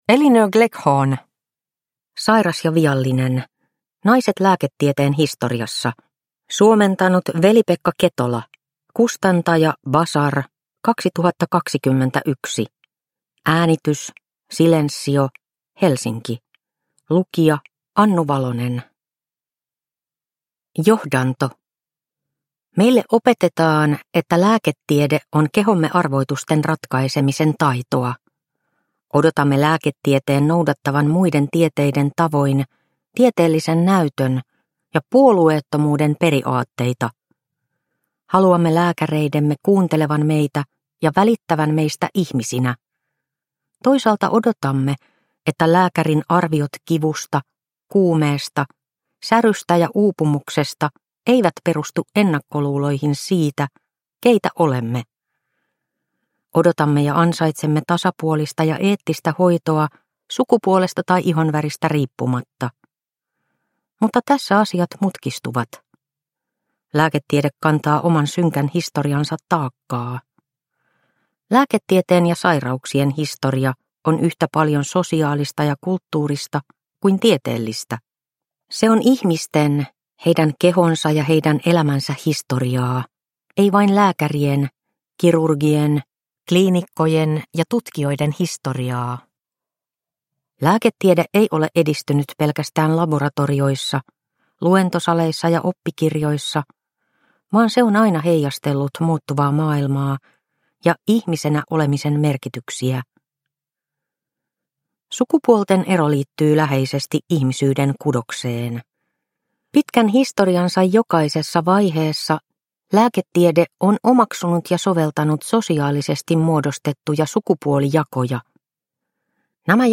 Sairas ja viallinen – Ljudbok – Laddas ner